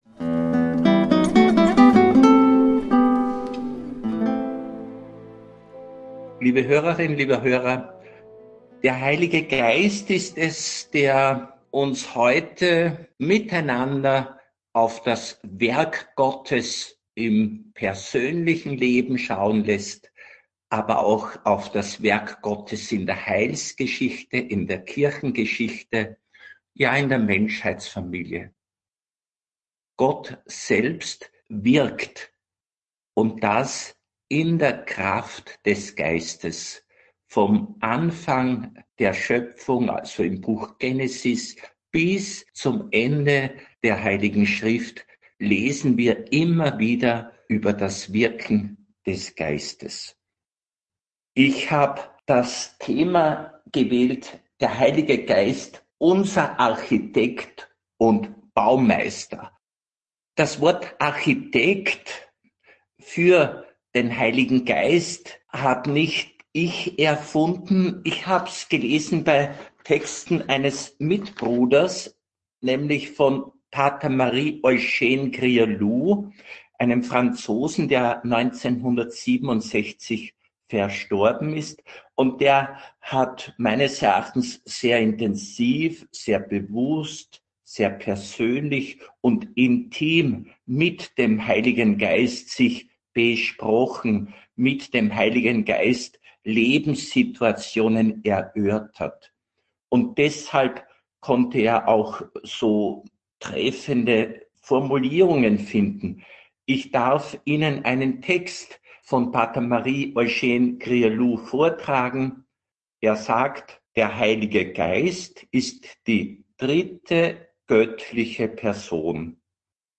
Gott hat eine Gesamtschau und wirkt Heilsgeschichte. (Radio Maria Aufzeichnung vom 21.5.2025) Mehr